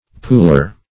Pooler \Pool"er\, n.